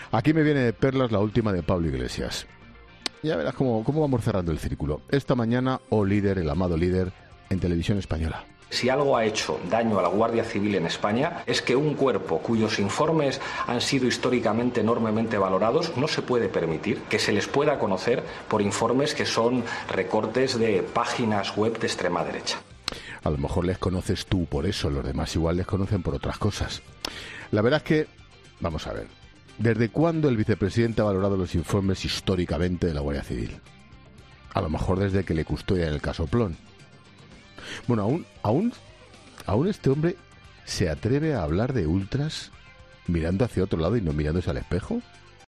Ángel Expósito ha aprovechado su monólogo inicial en ‘La Linterna’ de este lunes para arremeter contra Pablo Iglesias por sus últimas declaraciones.